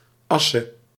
Asse (Dutch pronunciation: [ˈɑsə]
Nl-Asse.ogg.mp3